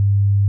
100Hz.wav